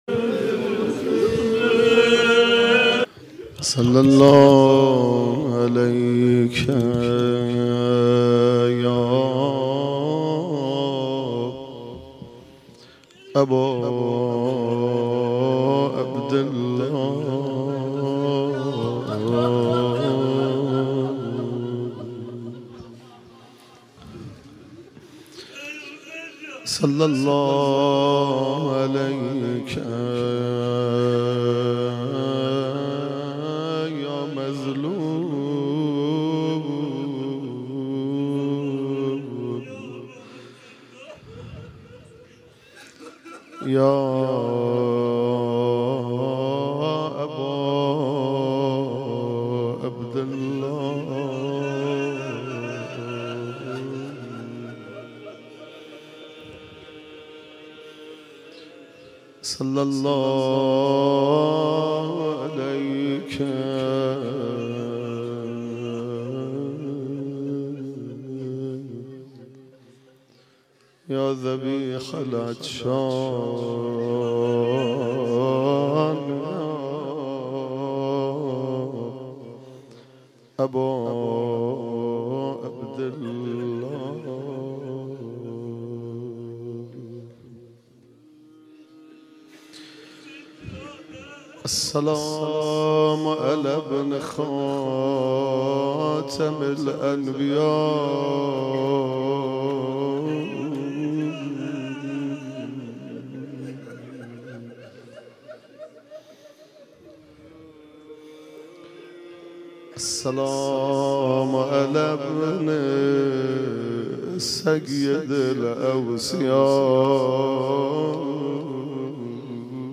مداحی و روضه خوانی
در شب عاشورای محرم الحرام 1396.